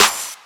Clap 4.wav